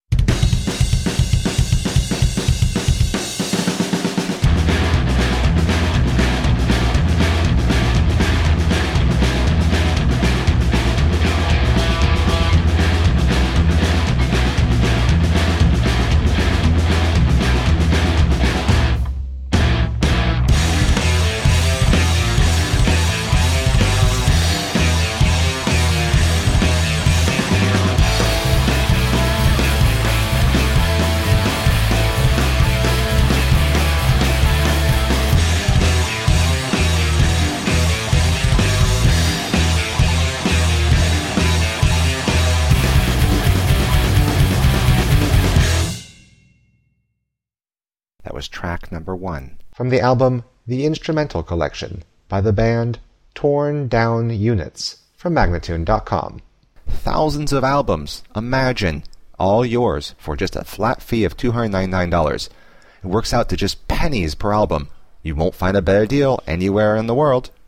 A whole new kind of instrumental rock.
Tagged as: Alt Rock, Hard Rock, Grungy Rock, Instrumental